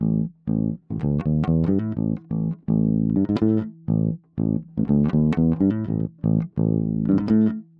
08 Bass PT3.wav